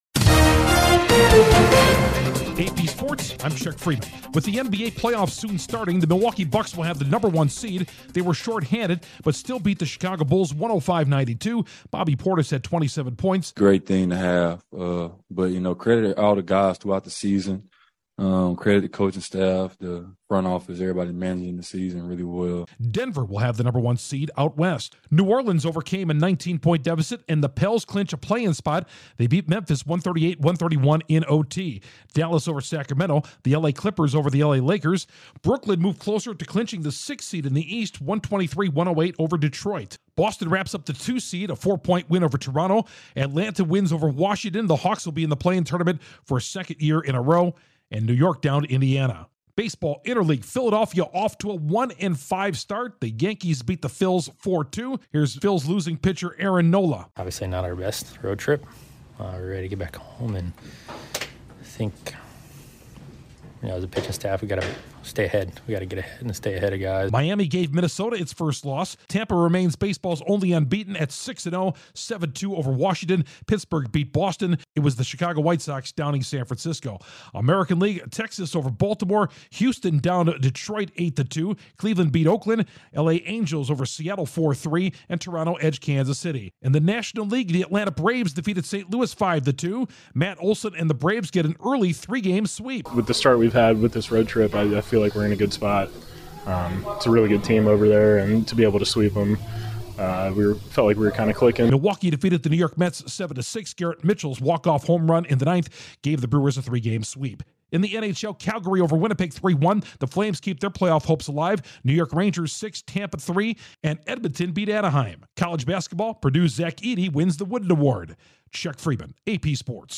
The Bucks wrap up the NBA's No. 1 seed, the Nuggets have the West's top seed, the Rays remain baseball's only unbeaten andPurdue's Edey takes the Wooden Award. Correspondent